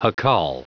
Prononciation du mot jacal en anglais (fichier audio)
Prononciation du mot : jacal